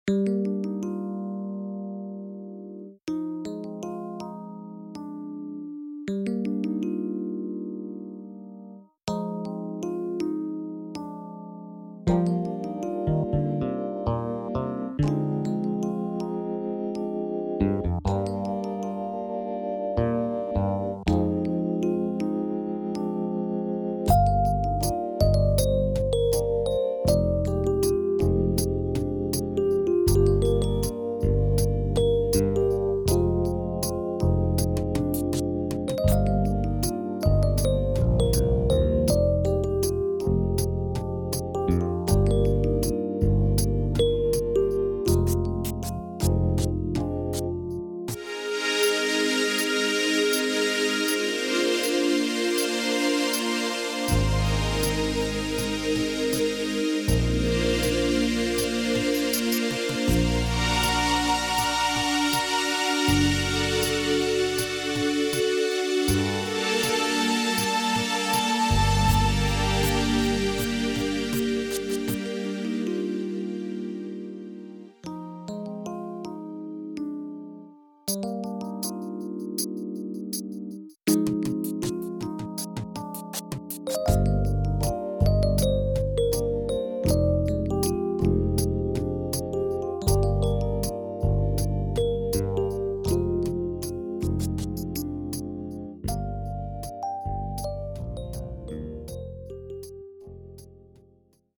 ManyOne comes with a wide range of sounds in four categories: electric pianos, ensembles, organs, and waveforms (which include classic analog waves) that are all selectable from the easy to use sound browser.
with a drum kit